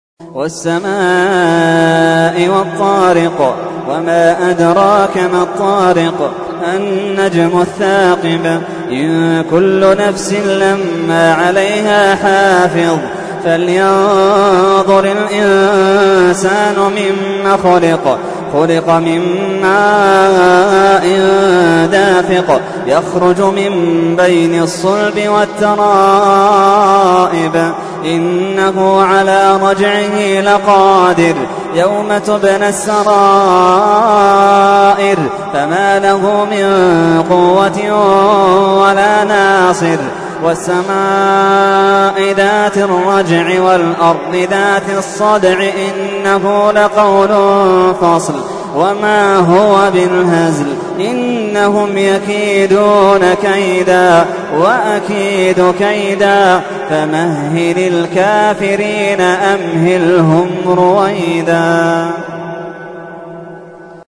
تحميل : 86. سورة الطارق / القارئ محمد اللحيدان / القرآن الكريم / موقع يا حسين